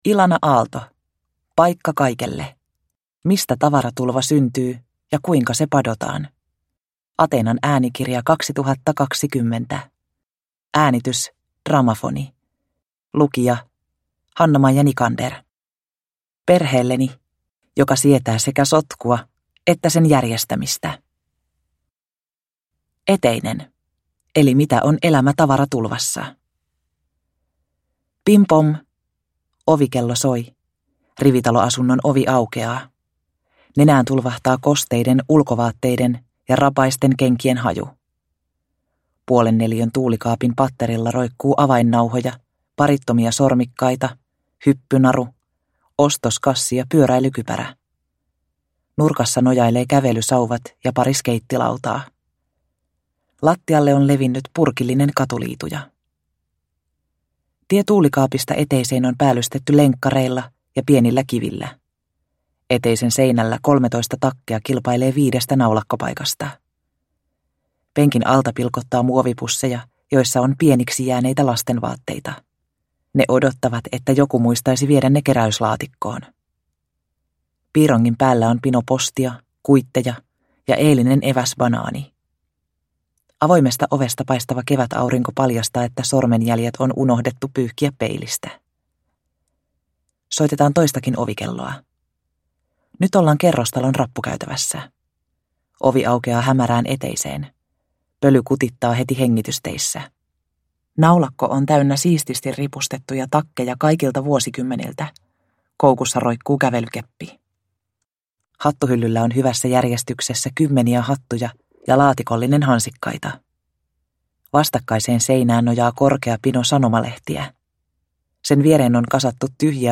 Paikka kaikelle – Ljudbok – Laddas ner